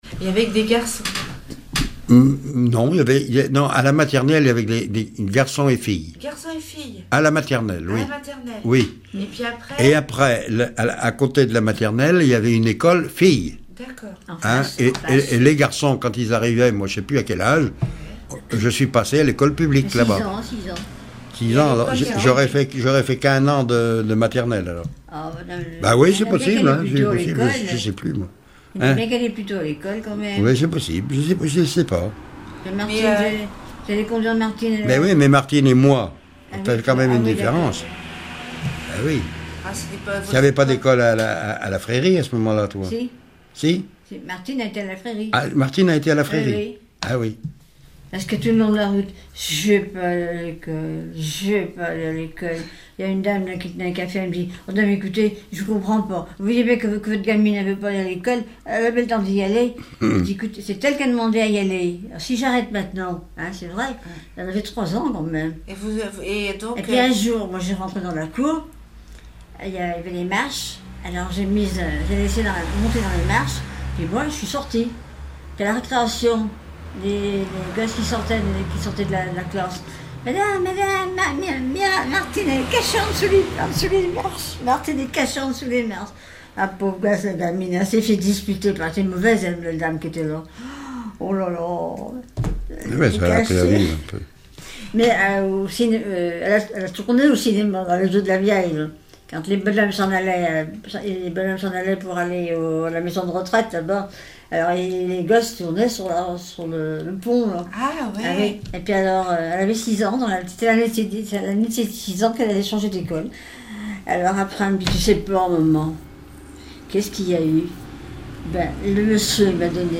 écolier(s), école ; chanteur(s), chant, chanson, chansonnette ;
Catégorie Témoignage